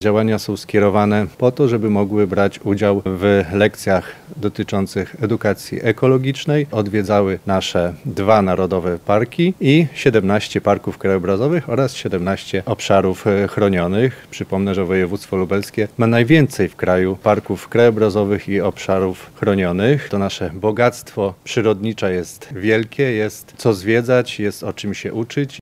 Na ten cel przeznaczyliśmy 1 mln 75 tys. zł – mówi wicemarszałek województwa lubelskiego, Marek Wojciechowski.